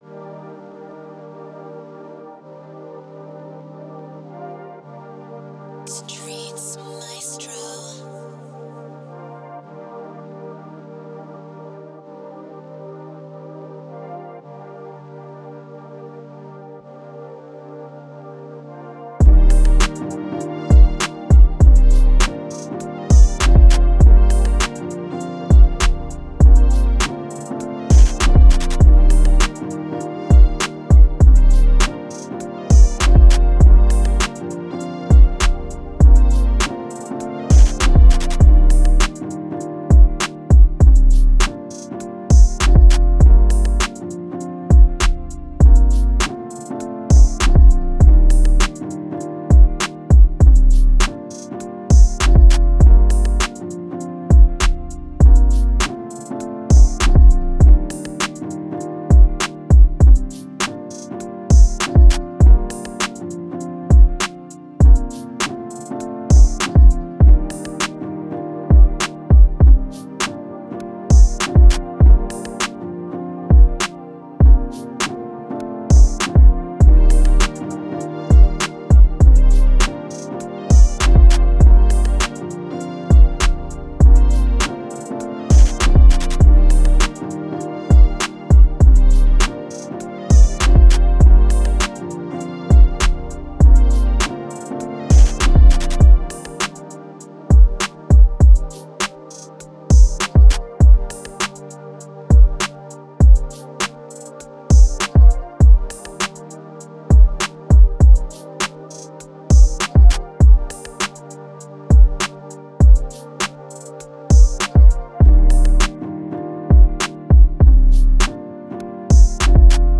R&B Type Beat
Moods: laid back, mellow, smooth
Genre: R&B
Tempo: 100